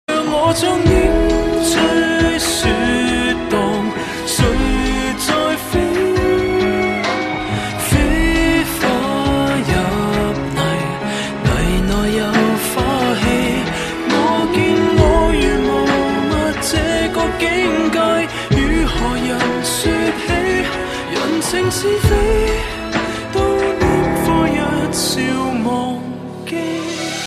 M4R铃声, MP3铃声, 华语歌曲 93 首发日期：2018-05-14 21:13 星期一